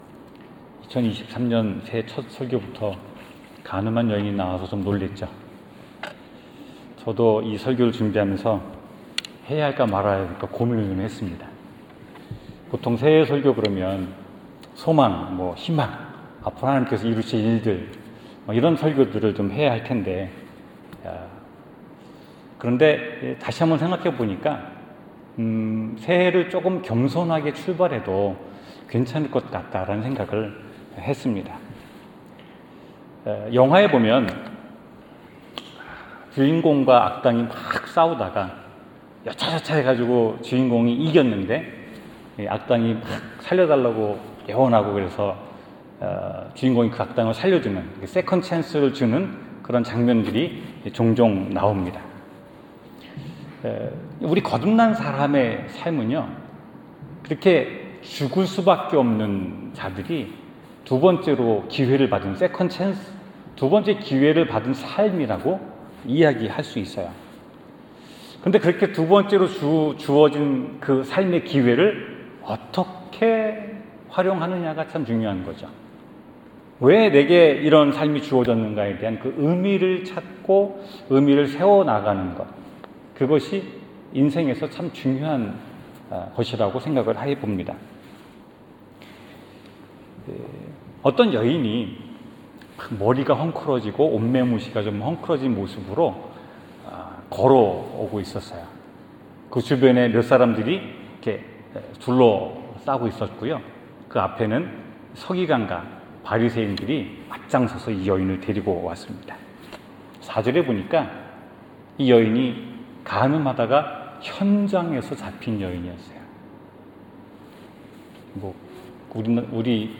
성경 : 요한복음 8:1-11절 설교